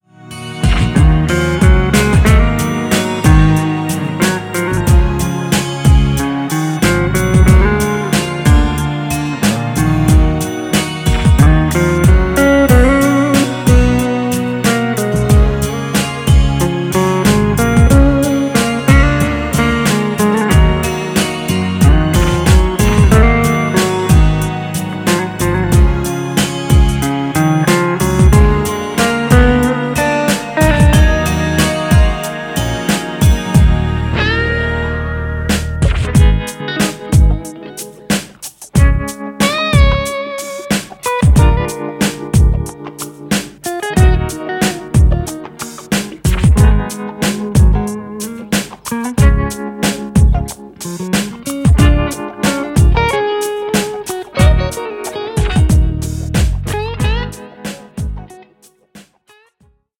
Instrumental
Ideenreich, einfühlsam und effektvoll
Dabei greift er nicht nur auf die E-Gitarre zurück